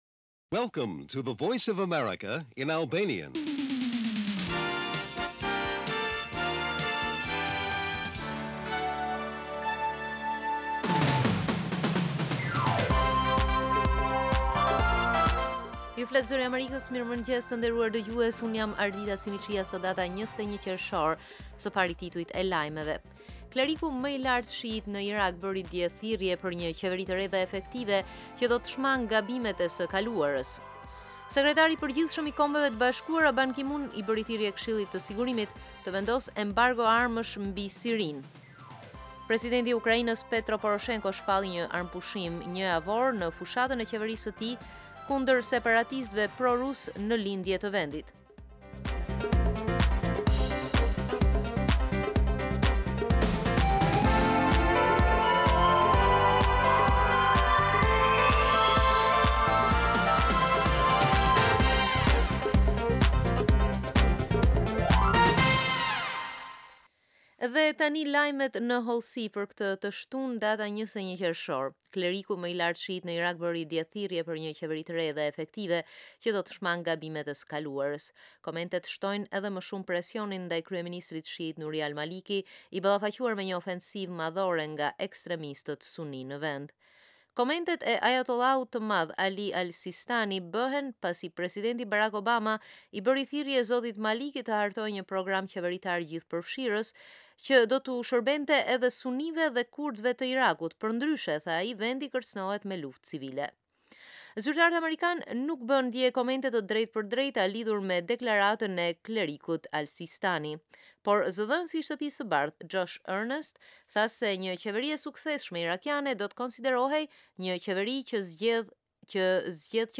Lajmet e mëngjesit